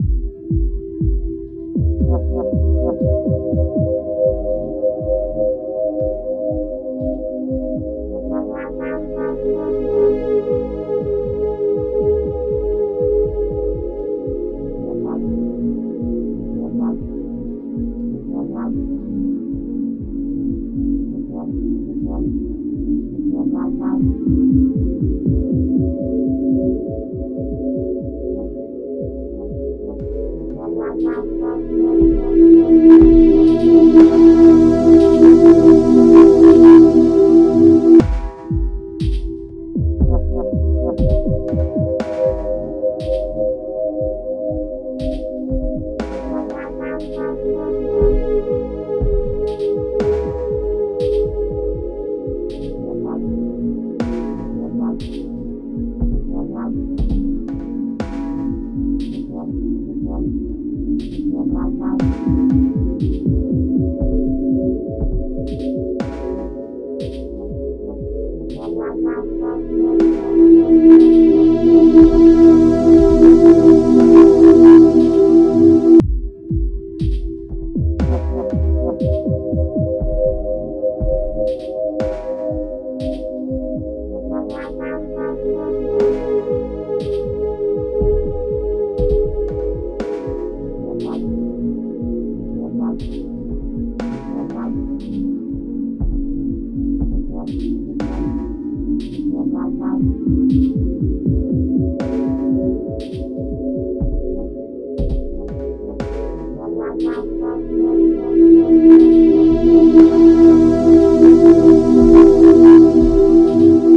music are now 16bits mono instead of stereo